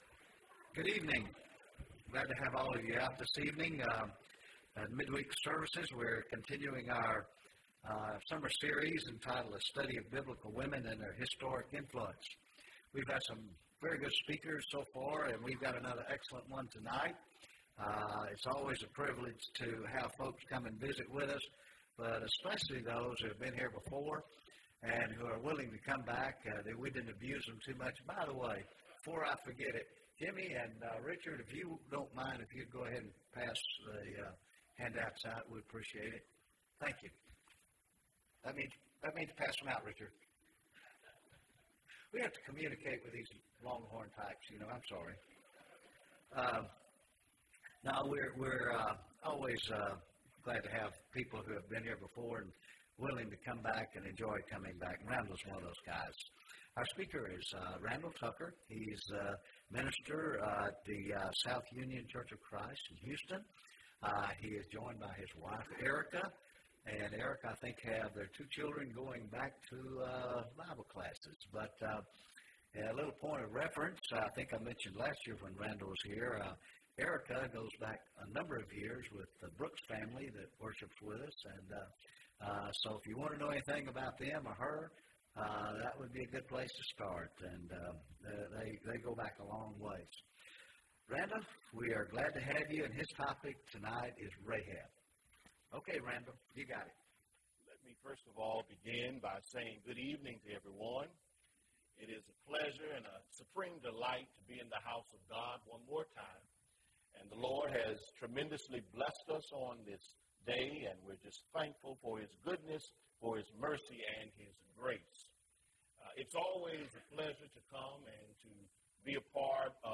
Rahab (7 of 10) – Bible Lesson Recording